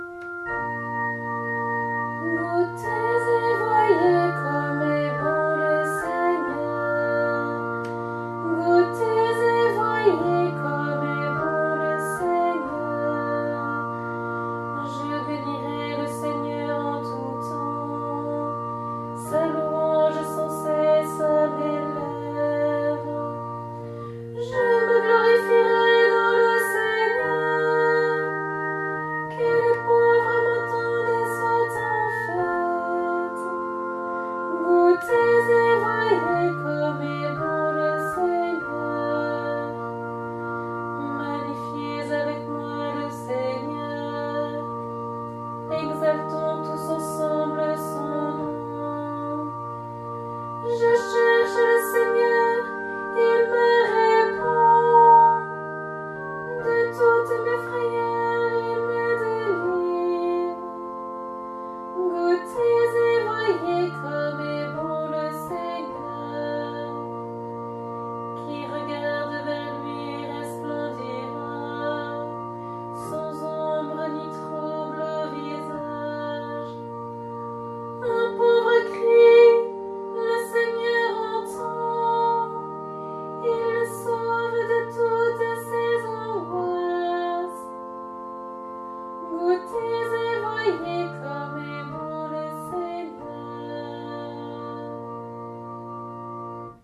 Antienne pour le 19e, 20e et 21e dimanche du Temps Ordinaire (année B)